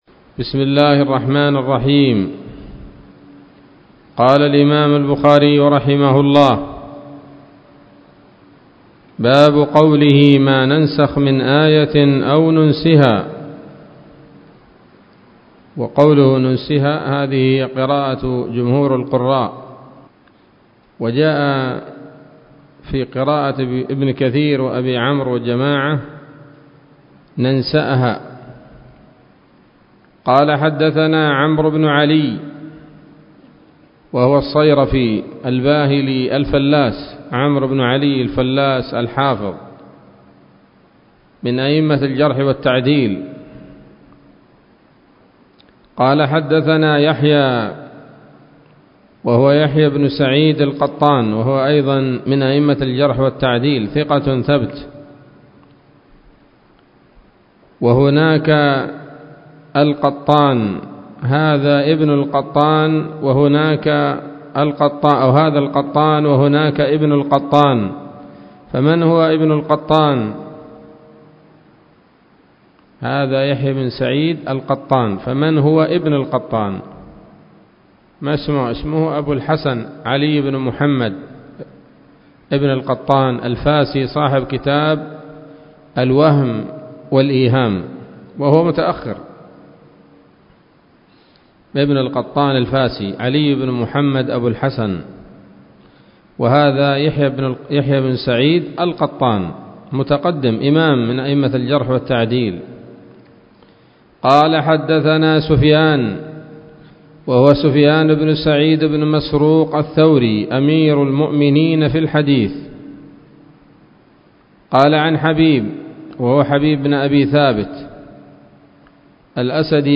الدرس الثامن من كتاب التفسير من صحيح الإمام البخاري